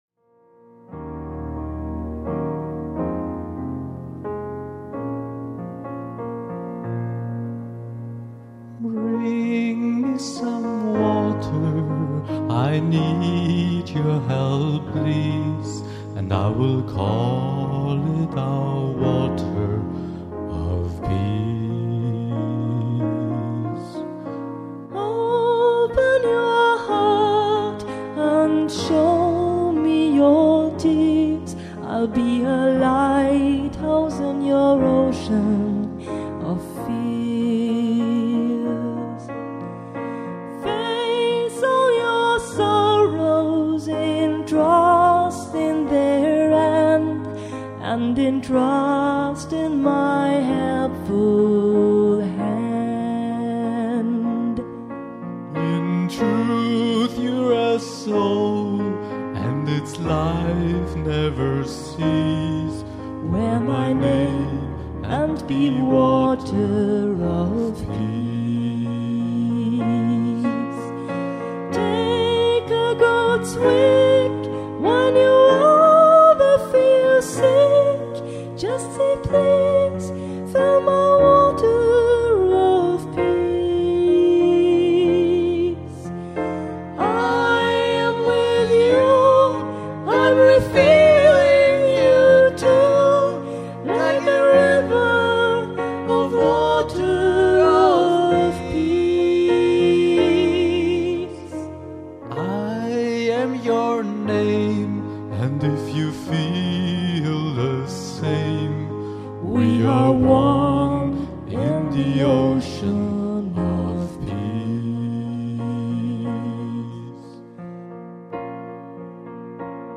Mehrere Titel wurden als Duett aufgenommen: